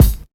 break_kick_13.wav